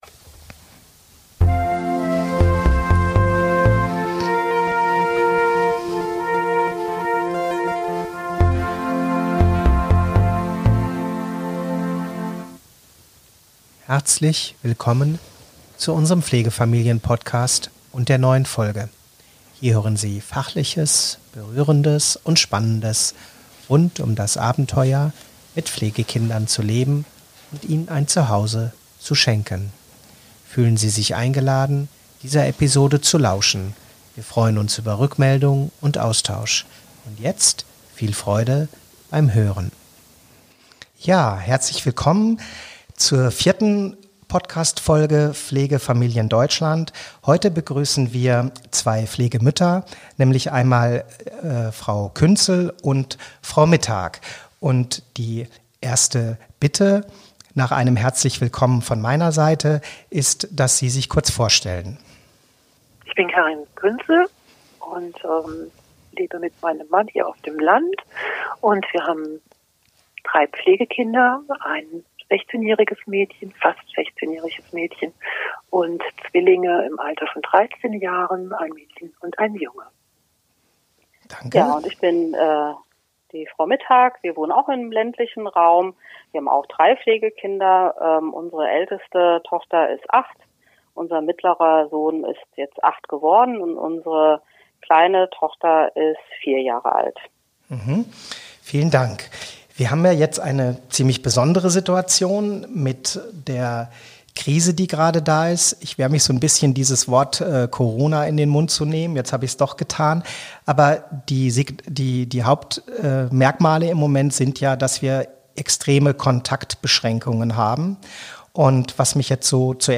Ein kurzweiliges Interview mit einem Ausblick darauf, was sich vielleicht aus dieser Zeit lernen lässt.